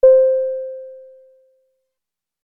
ding
aircraft airplane alert bing ding elevator event ping sound effect free sound royalty free Sound Effects